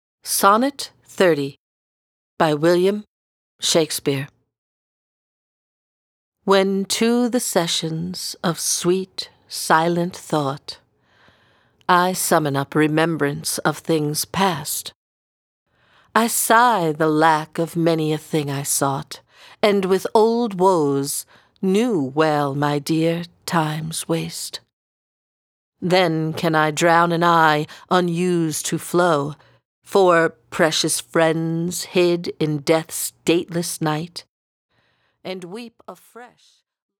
(Narrator)